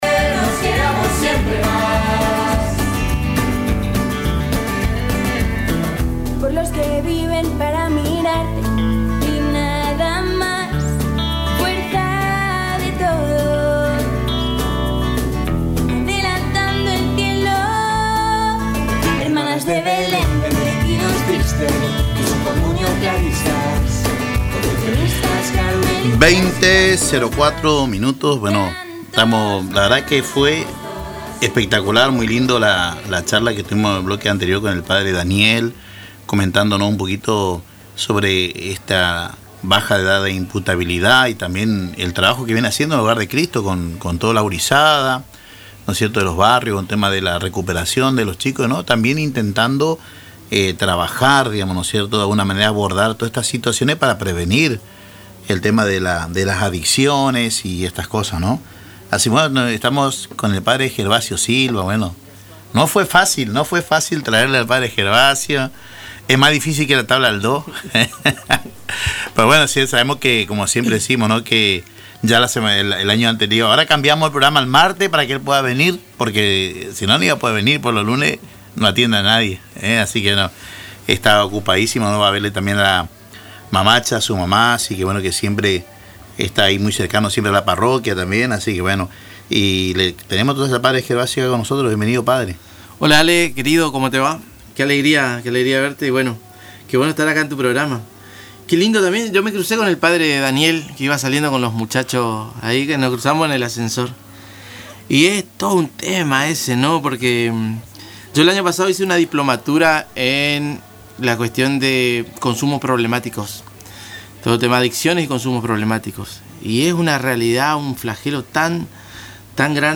entrevista con Radio Tupa Mbae